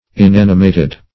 Meaning of inanimated. inanimated synonyms, pronunciation, spelling and more from Free Dictionary.
Search Result for " inanimated" : The Collaborative International Dictionary of English v.0.48: Inanimated \In*an"i*ma`ted\, a. Destitute of life; lacking animation; unanimated.